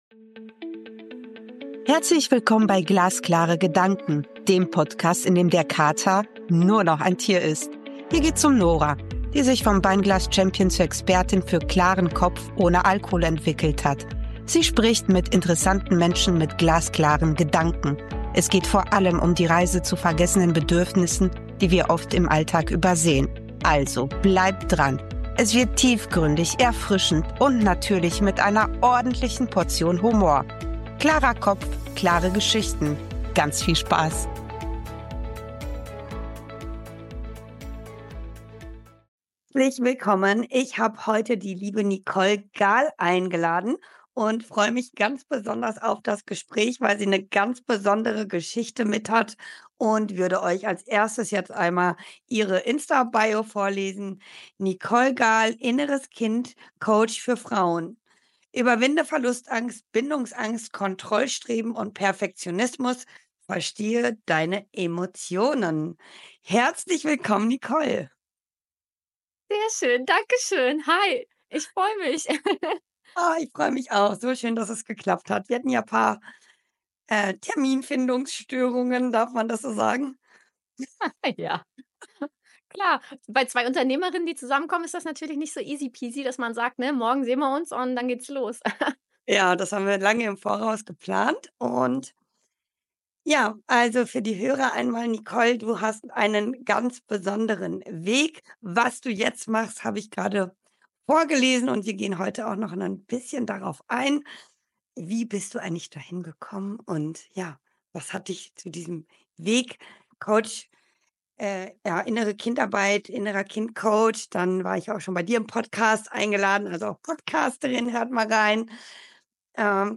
Ein berührendes Gespräch über innere Freiheit, Mut zur Veränderung und die Rückkehr zu uns selbst.